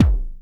3 Harsh Realm MFB Kick.wav